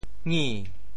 「儗」字用潮州話怎麼說？
儗 部首拼音 部首 亻 总笔划 16 部外笔划 14 普通话 nǐ yì 潮州发音 潮州 ngi2 文 中文解释 擬 <動> (形聲。
ngi2.mp3